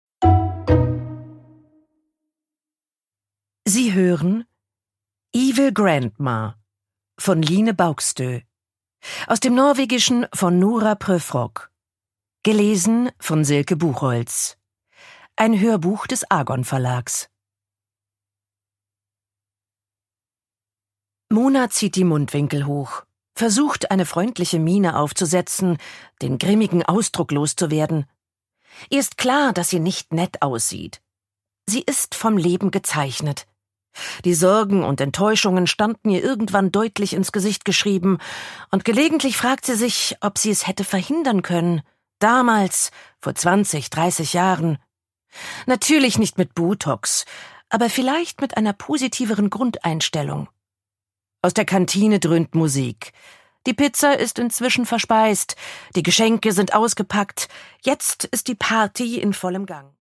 Line Baugstø: Evil Grandma (Ungekürzte Lesung)
Produkttyp: Hörbuch-Download